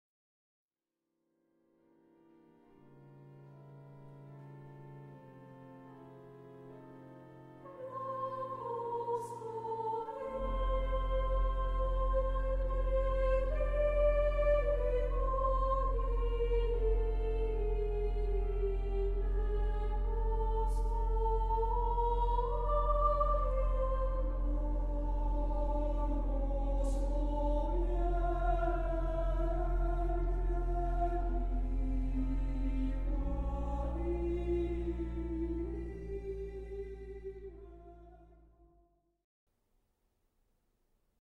varhany